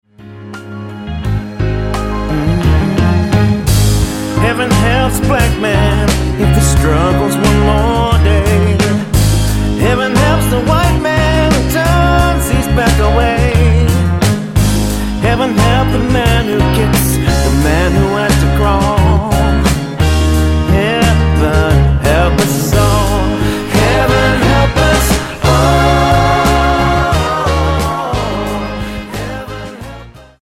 Tonart:Ab-A Multifile (kein Sofortdownload.
Die besten Playbacks Instrumentals und Karaoke Versionen .